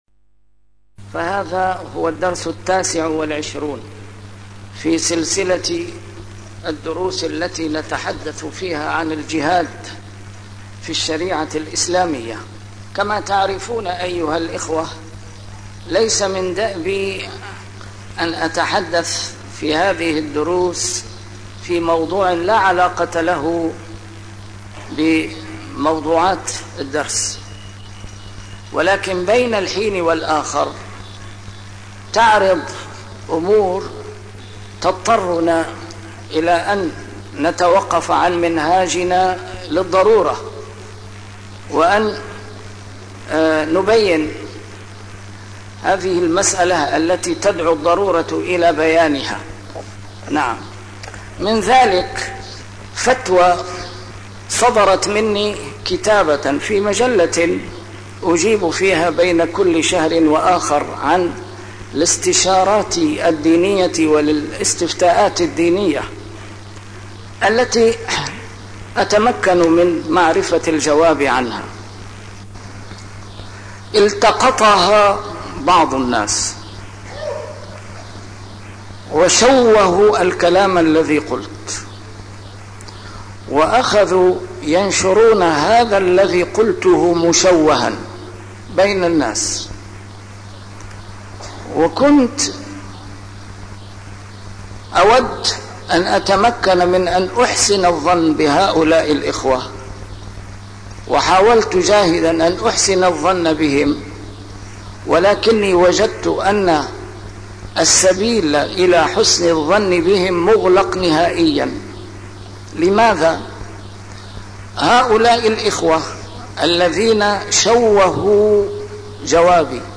A MARTYR SCHOLAR: IMAM MUHAMMAD SAEED RAMADAN AL-BOUTI - الدروس العلمية - الجهاد في الإسلام - تسجيل قديم - الدرس التاسع والعشرون: فلسطين والسبيل الوحيد لاستنقاذها - ج 2